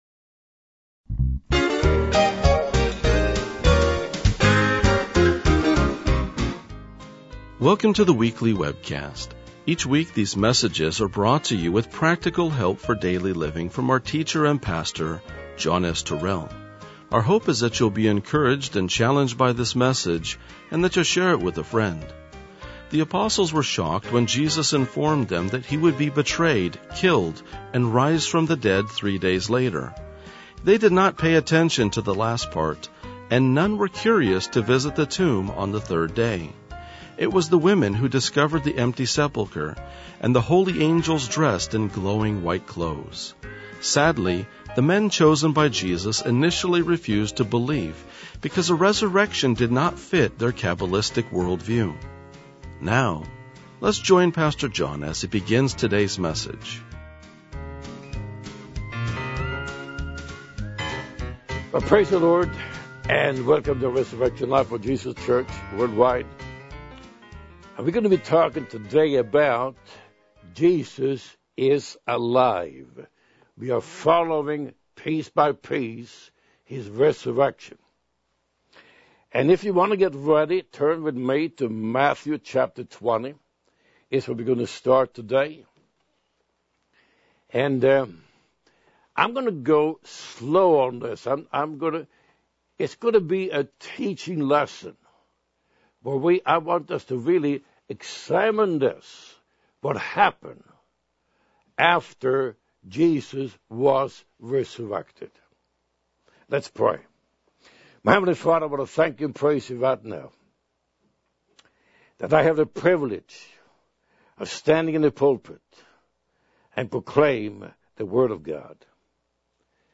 RLJ-1991-Sermon.mp3